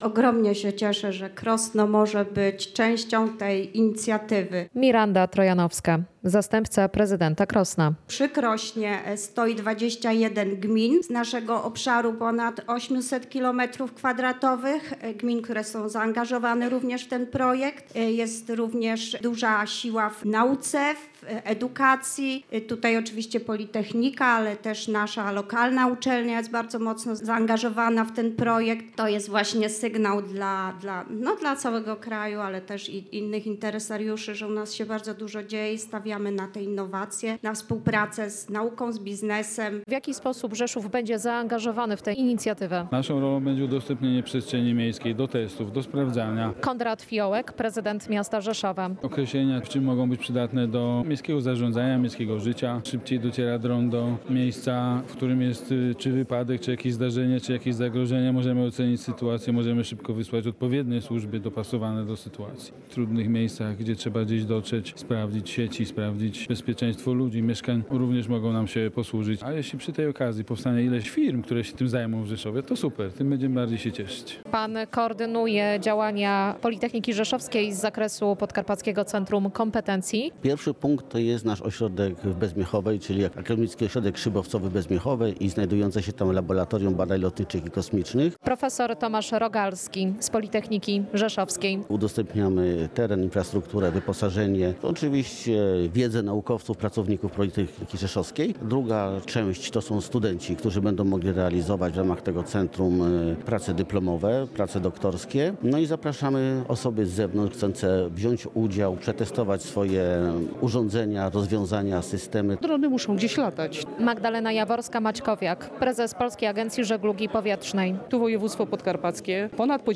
Podkarpacie stanie się kluczowym miejscem testowania technologii dronowych w Polsce • Relacje reporterskie • Polskie Radio Rzeszów